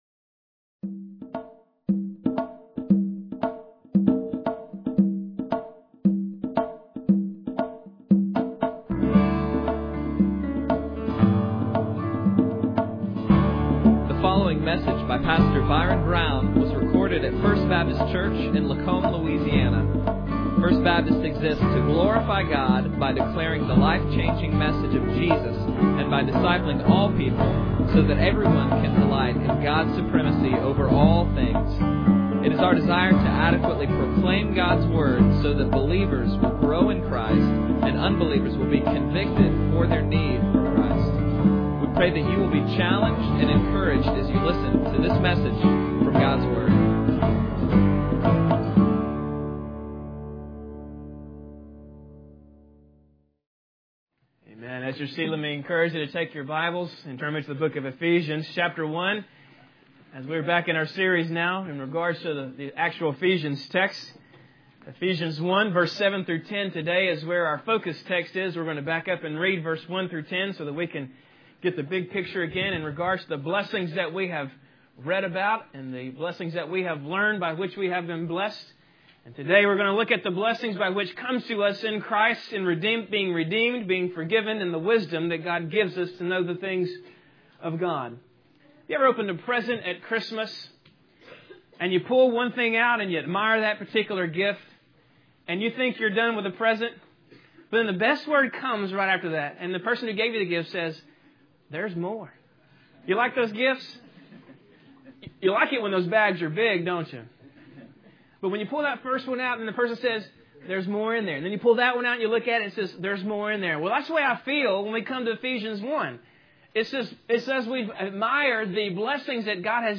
Bible Text: Ephesians 1:7-10 | Preacher